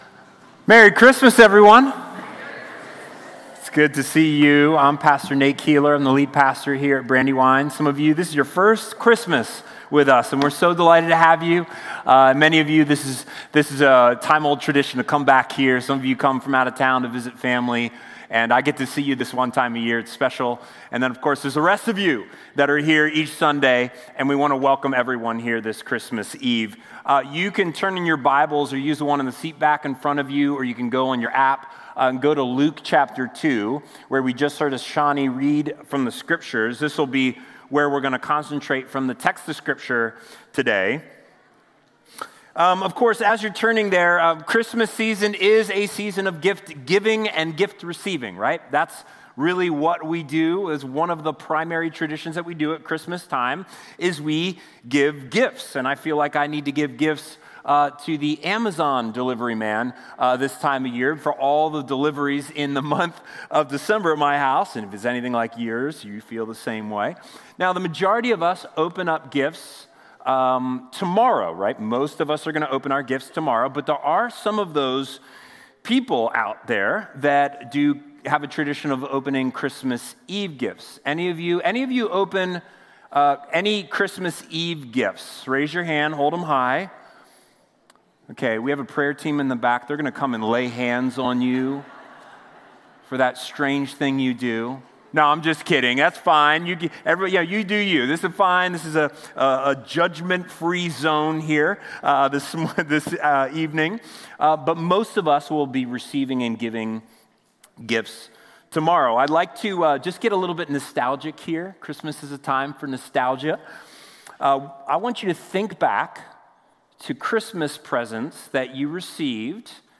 A message from the series "Advent: Long Foretold." The prophet Micah foretells of a king who is both ancient and future. Learn why we all need a king and how to make Jesus our King.